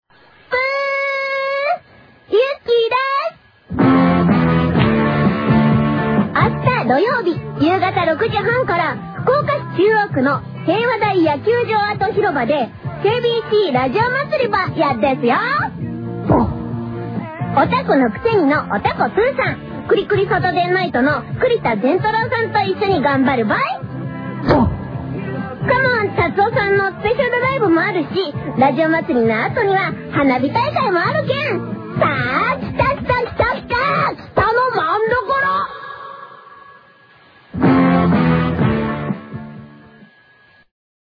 【イベントCM】